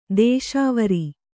♪ dēśāvari